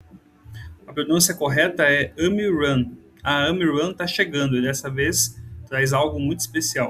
Usar voz como se fosse um criança convidando para a corrida